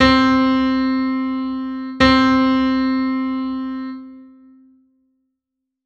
Como referencia de afinación podedes reproducir os seguintes arquivos de audio co son de cada unha das notas.
Nota DO